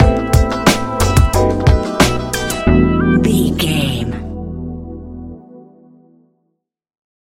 Ionian/Major
E♭
laid back
Lounge
sparse
new age
chilled electronica
ambient
atmospheric